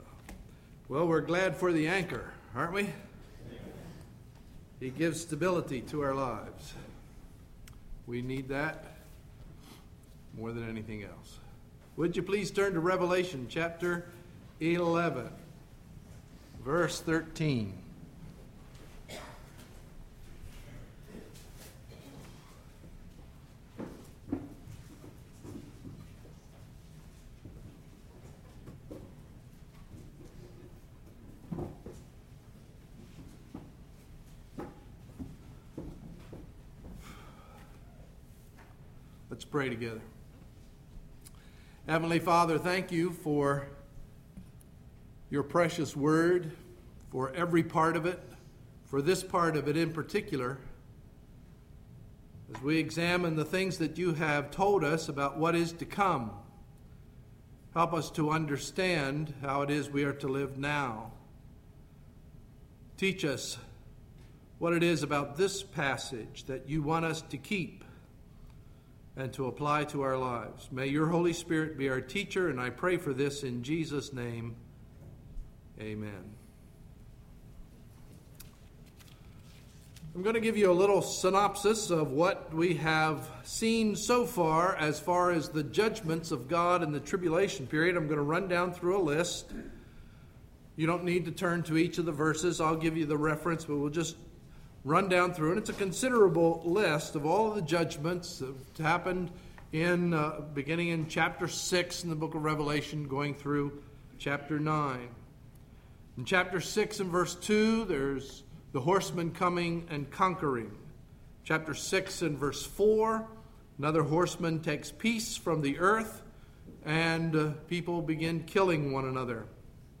Sunday, September 11, 2011 – Morning Message